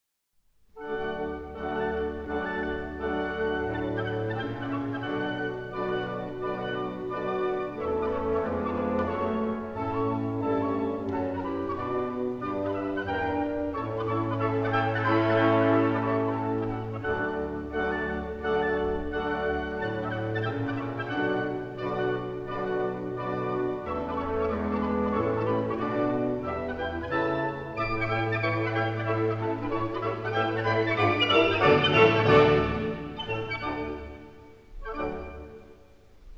in a 1960 stereo recording